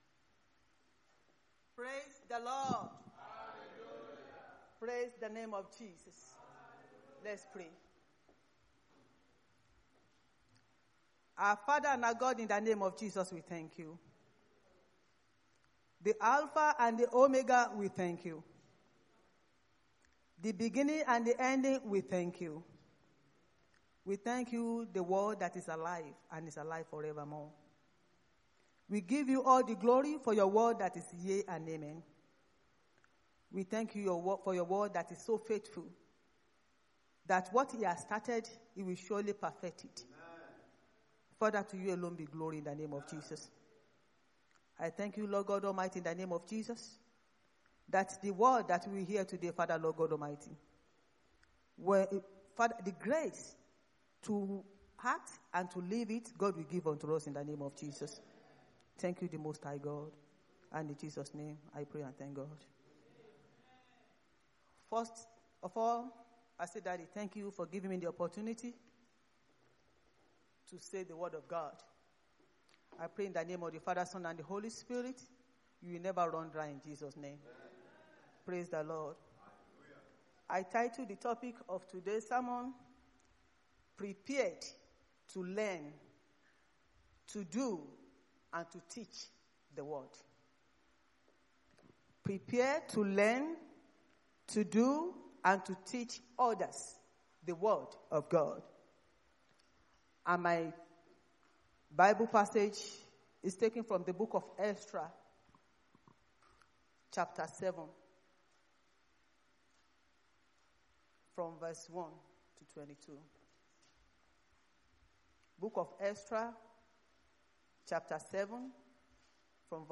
Sunday Sermon: Prepared To Learn, To Do And To Teach Others The Word | RCCG-HOUSE OF GLORY
Service Type: Sunday Church Service